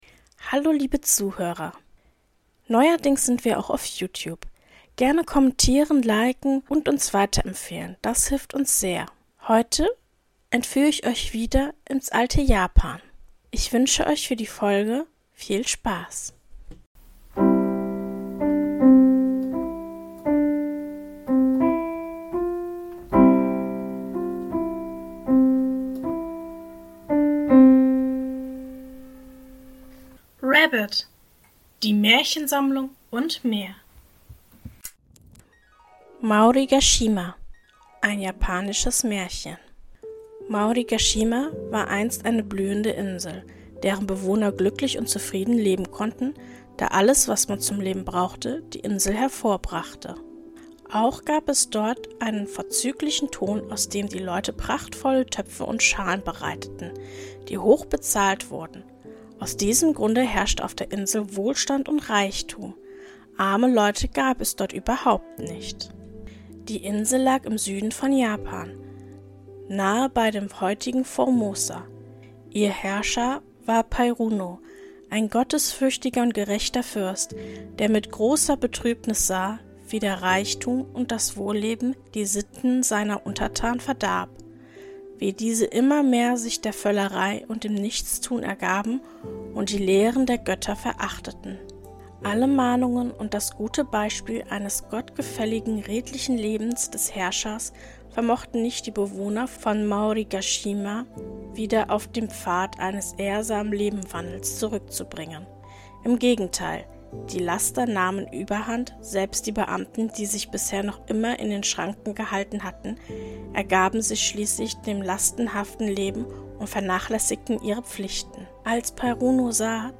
In der heutigen Folge lese ich Folgendes vor: 1. Maorigashima. 2.Der Hase und der Dachs. 3.